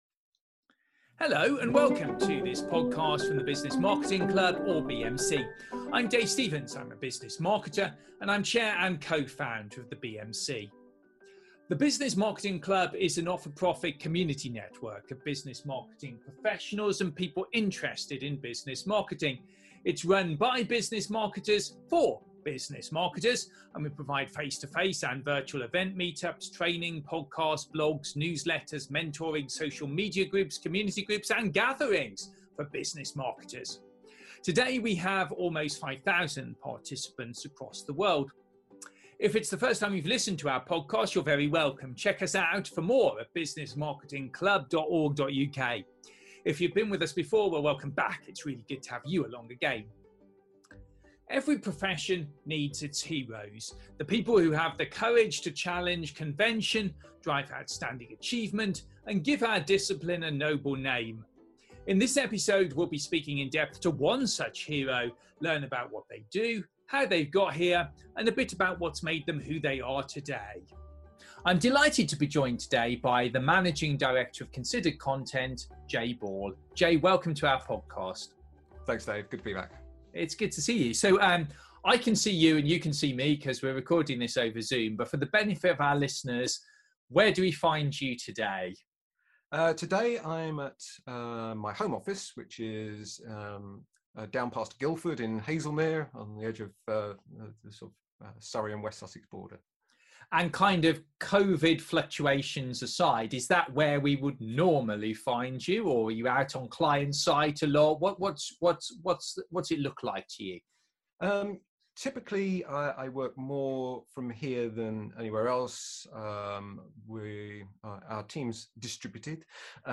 Continuing a series of in-depth interviews with some of Business Marketing’s heroes.